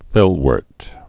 (fĕlwûrt, -wôrt)